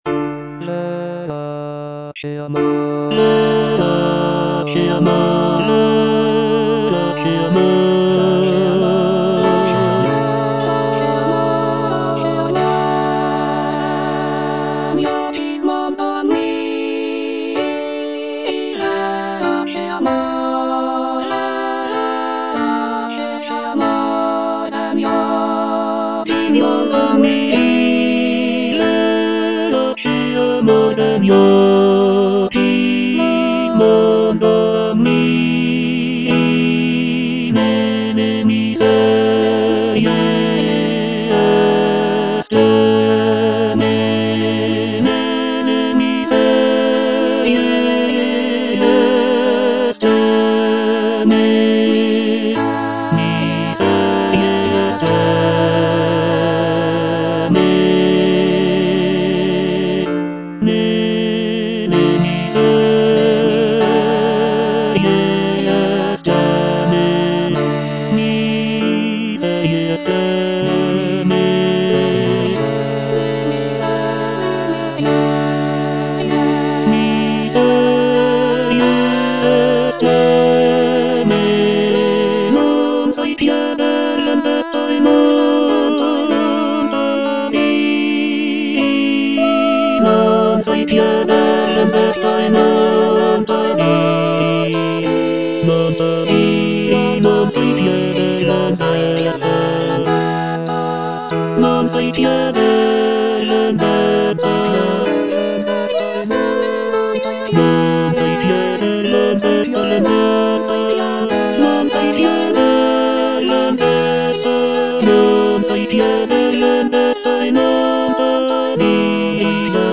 C. MONTEVERDI - LAMENTO D'ARIANNA (VERSION 2) Coro IV: Verace amor - Tenor (Emphasised voice and other voices) Ads stop: auto-stop
It is a deeply emotional lament aria that showcases the singer's ability to convey intense feelings of grief and despair.
The music is characterized by its expressive melodies and poignant harmonies, making it a powerful and moving example of early Baroque vocal music.